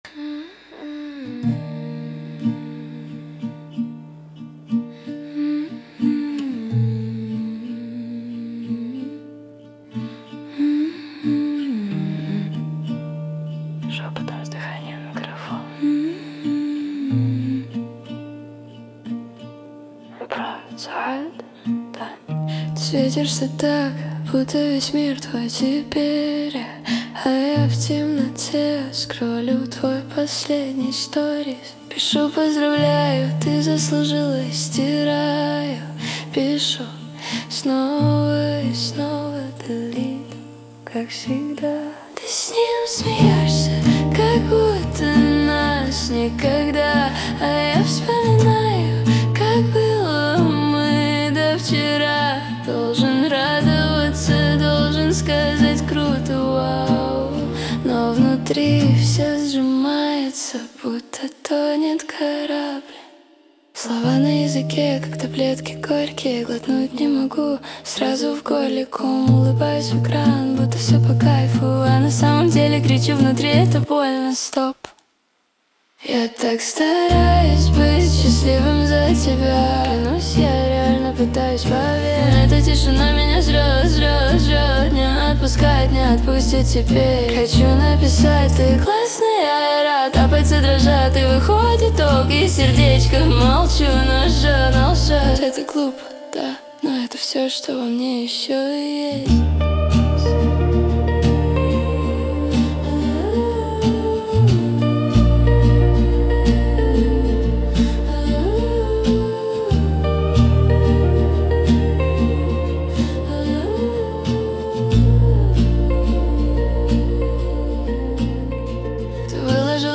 Шепотом с дыханием в микрофон